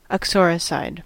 Ääntäminen
Ääntäminen US Haettu sana löytyi näillä lähdekielillä: englanti Käännös Substantiivit 1.